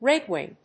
アクセント・音節réd・wìng